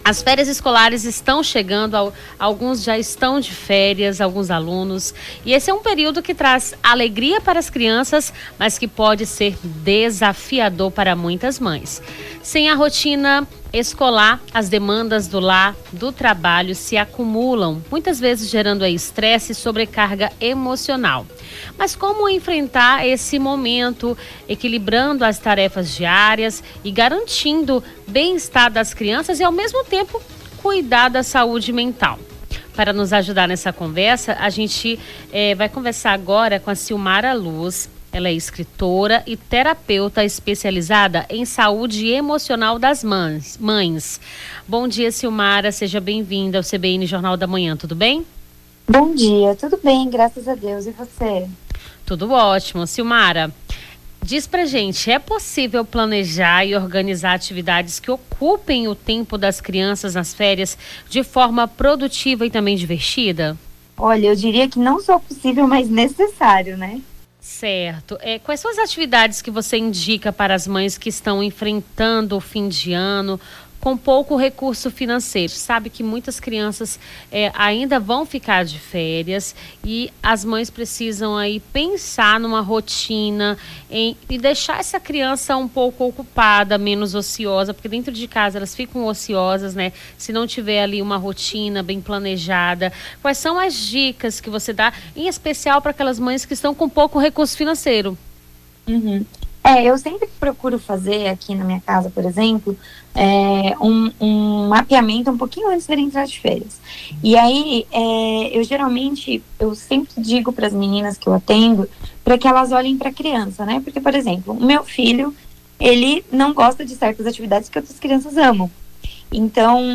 Nome do Artista - CENSURA - ENTREVISTA MÃES NAS FÉRIAS ESCOLARES (11-12-24).mp3